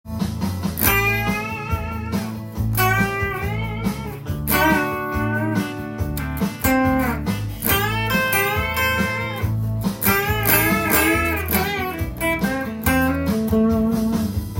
以上の弾き方を使いながらソロを弾いてみました。